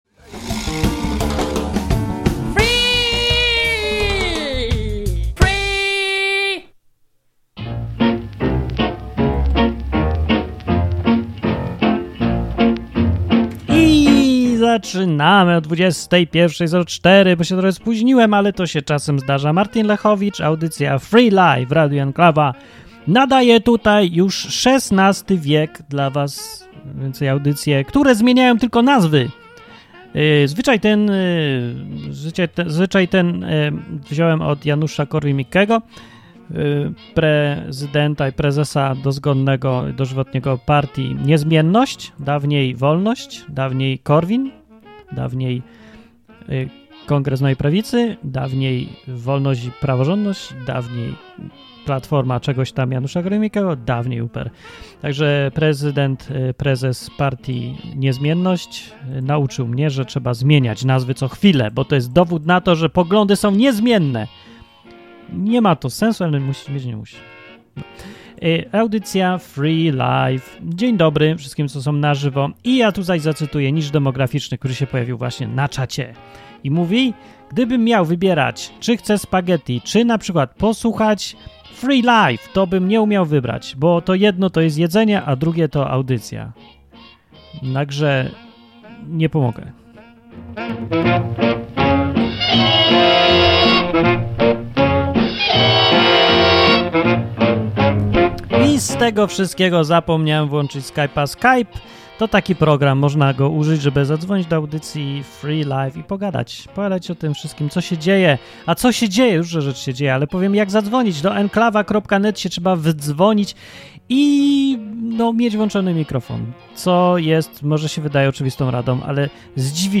Program dla wszystkich, którzy lubią luźne, dzikie, improwizowane audycje na żywo.